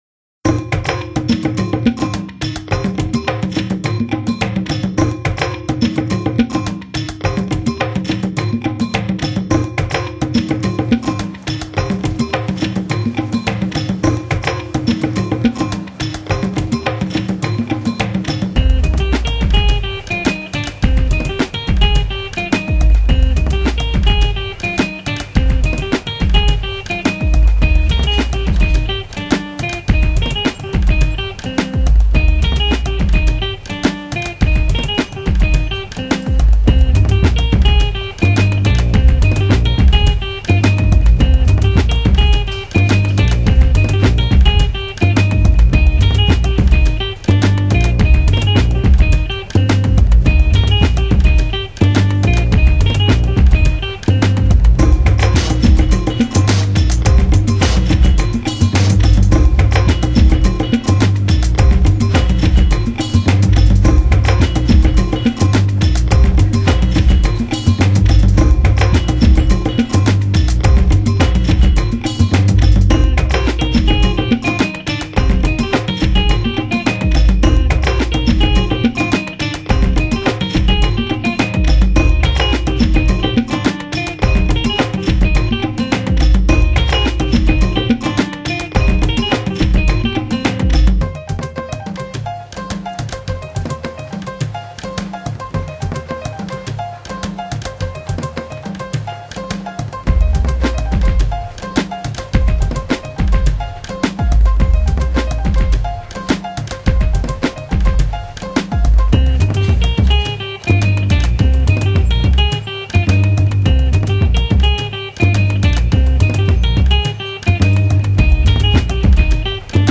Genre: Downtempo,House